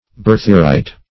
Search Result for " berthierite" : The Collaborative International Dictionary of English v.0.48: Berthierite \Ber"thi*er*ite\, n. [From Berthier, a French naturalist.]
berthierite.mp3